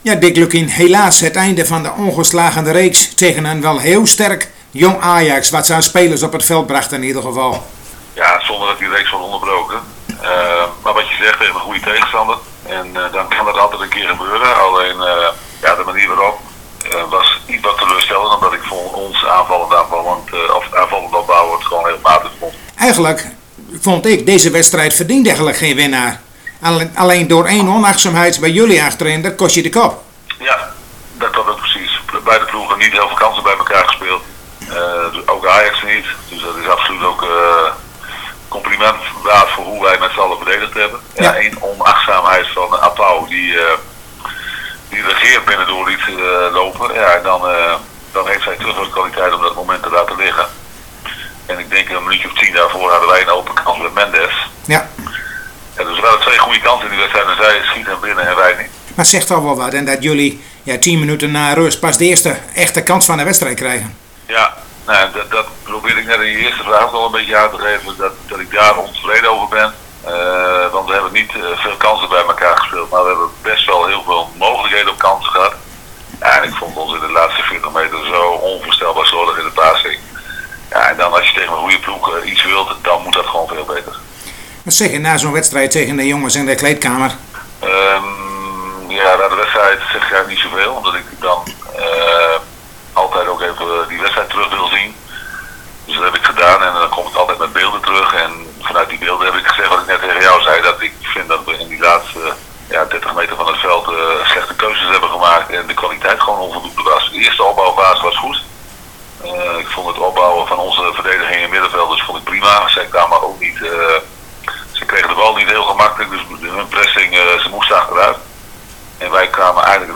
Na de teleurstelling van afgelopen maandag is het zaak voor de mannen van Dick Lukkien om morgen de rug te rechten en de drie punten in Emmen te houden. Wij spraken zojuist telefonisch met Dick Lukkien.